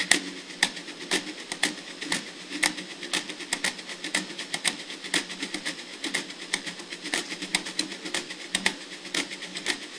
Звук перемотки